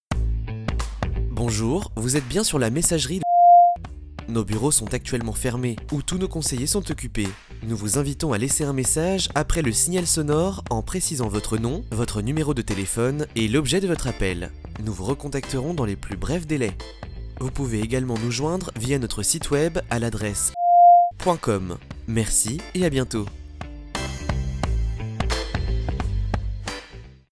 Nos comédiens voix-off donnent vie à votre attente téléphonique
Exemple répondeur